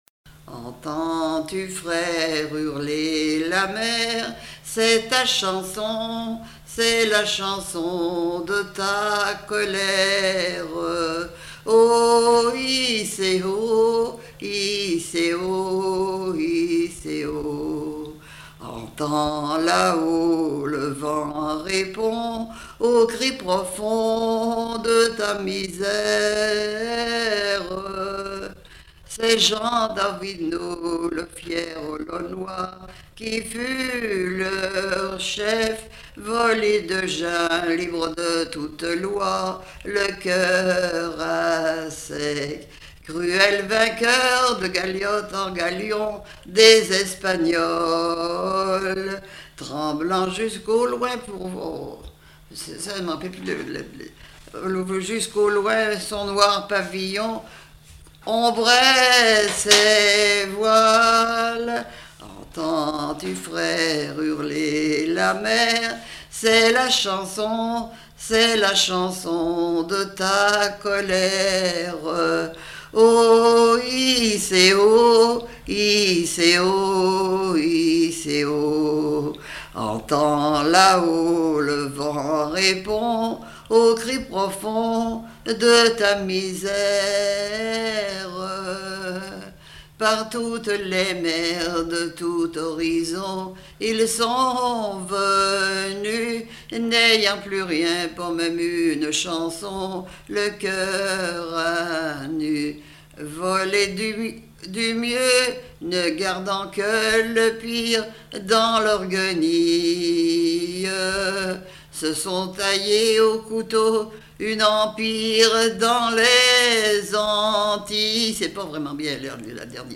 chanteur(s), chant, chanson, chansonnette
Genre strophique